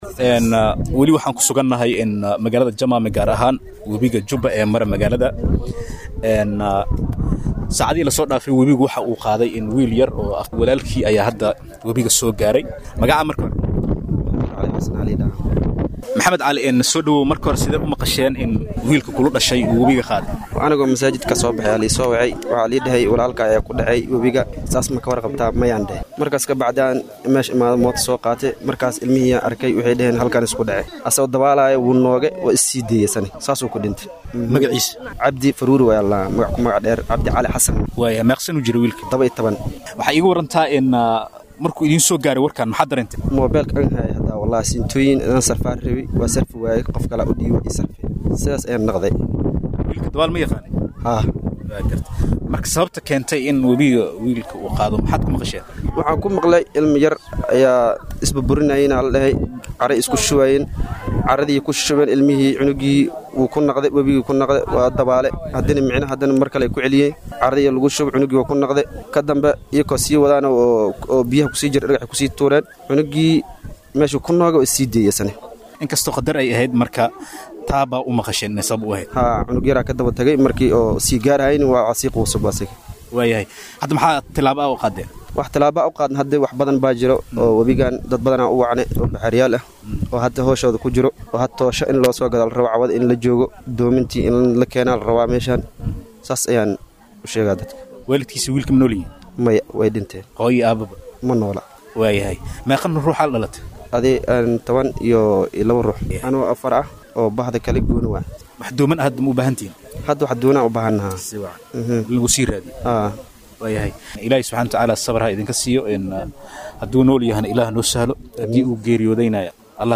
Wiil Dhalinyaro ah oo ku Dhintay Wabiga Degmada Jamaame.[WAREYSI]